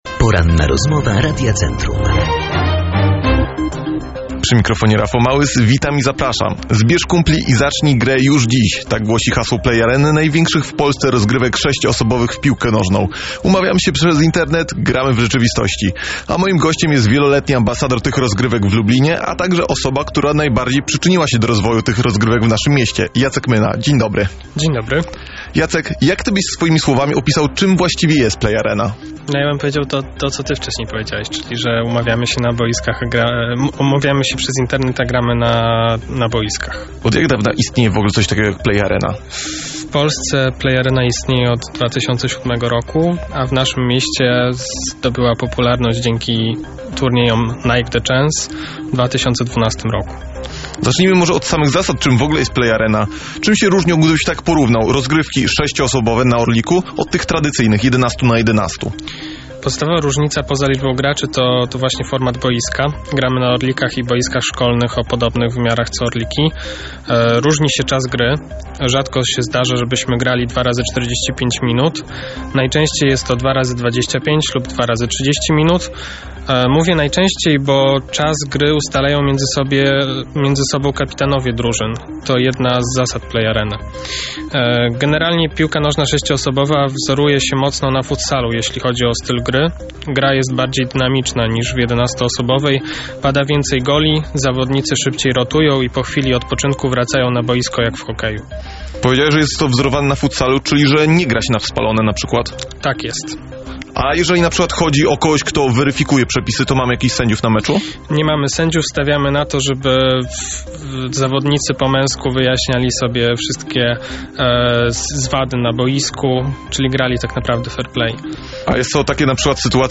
Rozmowa-po-edycji-1.mp3